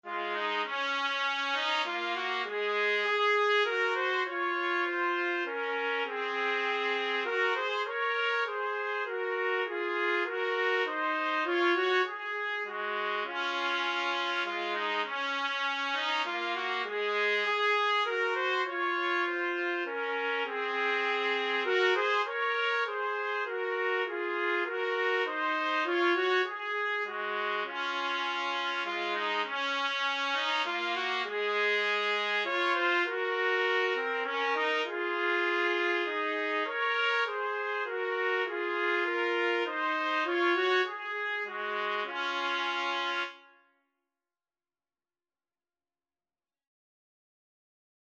Noel is an Early Modern English synonym of Christmas.
3/4 (View more 3/4 Music)
Trumpet Duet  (View more Easy Trumpet Duet Music)